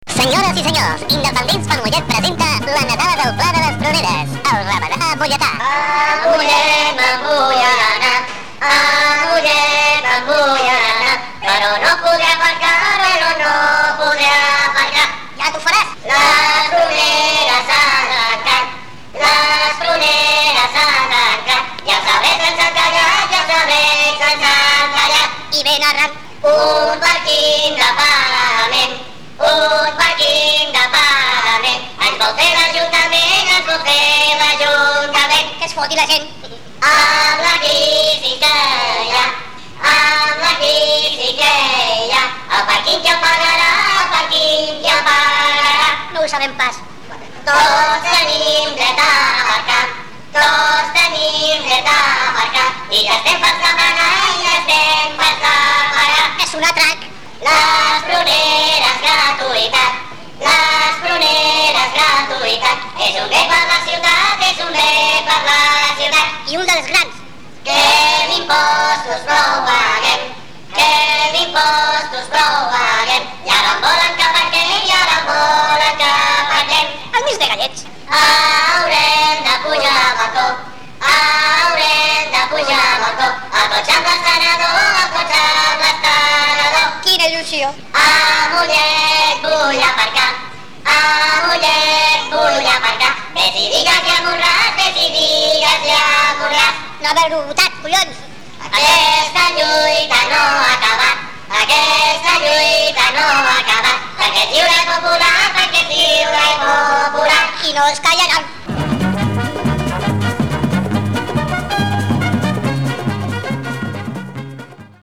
Una campanya com la de "Salvem el Pla de les Pruneres com aparcament gratuït" amb prop de 8.000 signatures de suport, es mereixia una nadala en pla de conya, com ens agrada a la gent d'Independents per Mollet (IxM). Així és que reconvertits en "barrufets irreverents" ens hem posat mans a l'obra per cantar-li als que manen i als que els suporten fent els muts, -partits de l'Ajuntament-, quatre veritats a  ritme de rabadà molletà.